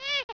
heul1.wav